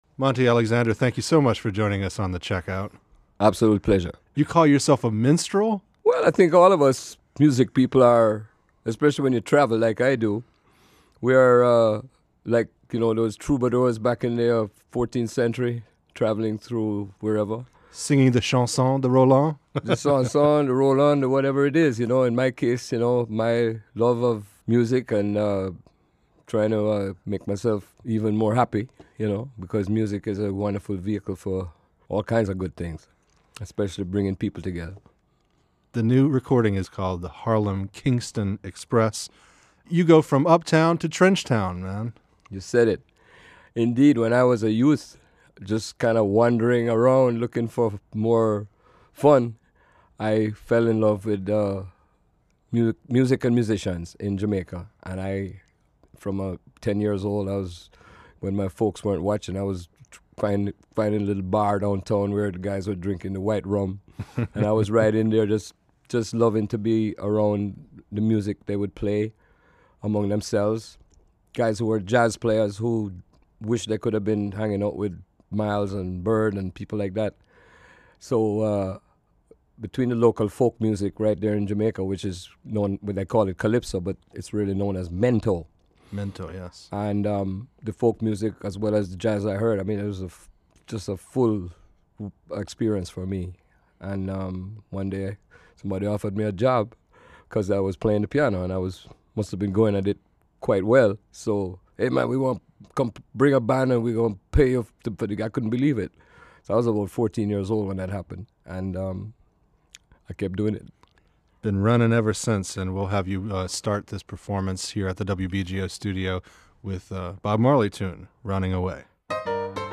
pianist